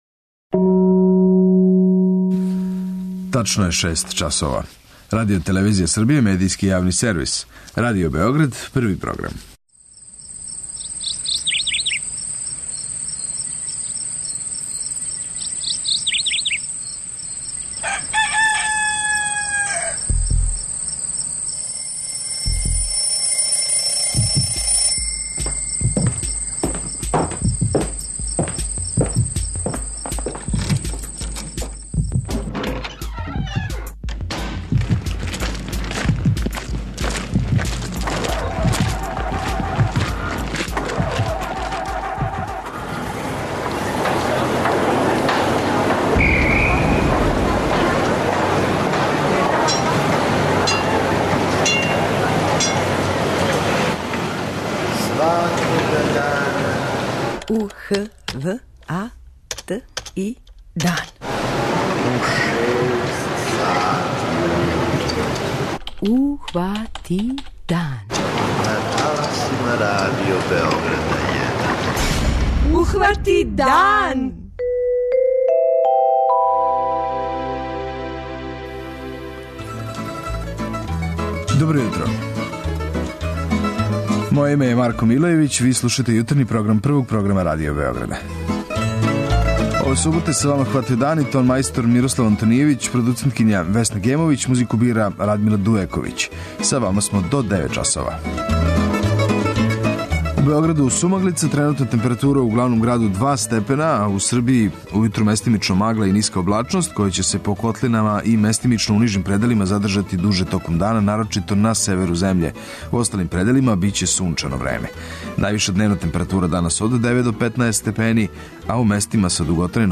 преузми : 85.97 MB Ухвати дан Autor: Група аутора Јутарњи програм Радио Београда 1!